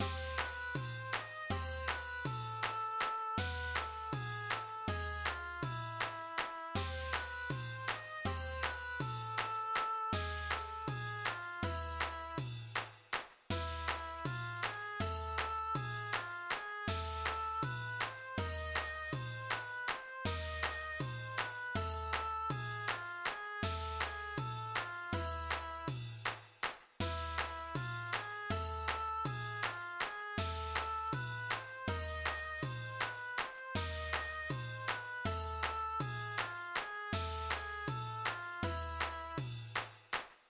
aranisches Klagelied
| } \fine } \relative c' { \time 9/8 \key c \major \tempo 4=80 \set Staff.beatStructure = 2,2,2,3 \set Staff.midiInstrument="oboe" c'8 b8 c8 dis8 c8 b8 a4.